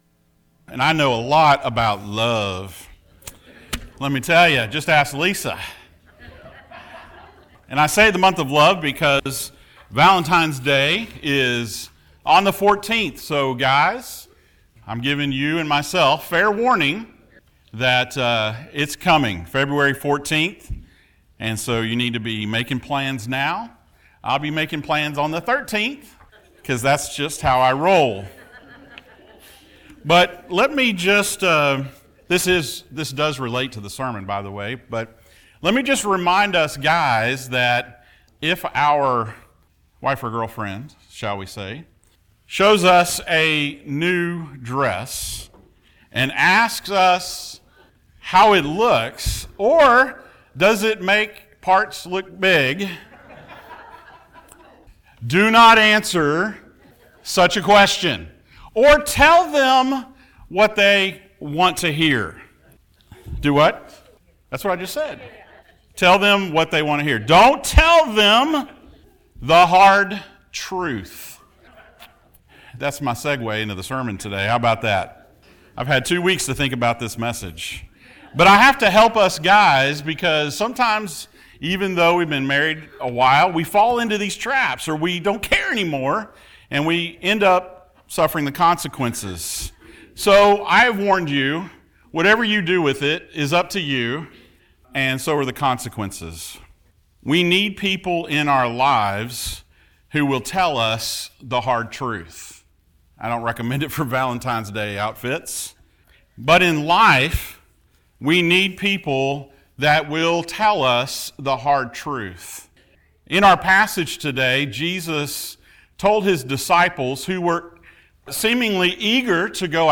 The Life and Ministry of Jesus Passage: Matthew 10:34-39 Service Type: Sunday Morning Thank you for joining us.